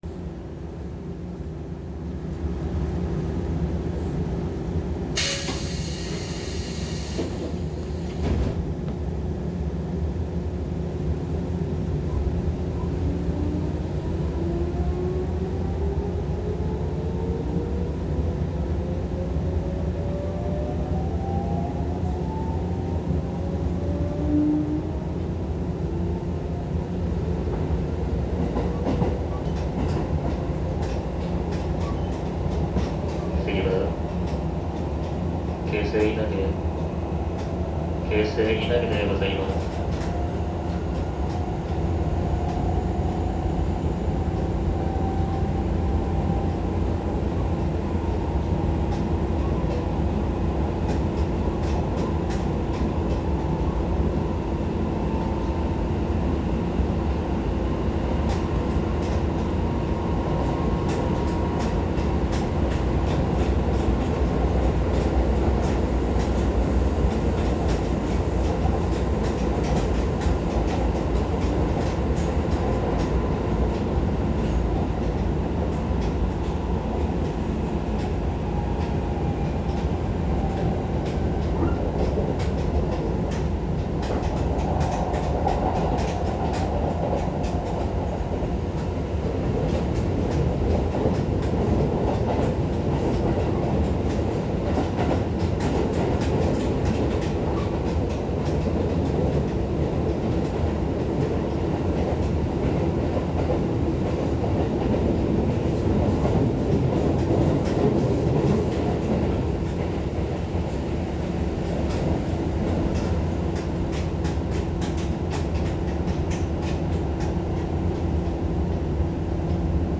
・8800形(ソフト変更後)走行音
【京成千葉線】検見川→稲毛（3分4秒：5.6MB）
変更前と比べて若干間延びした音になりました。
モーターは三菱製。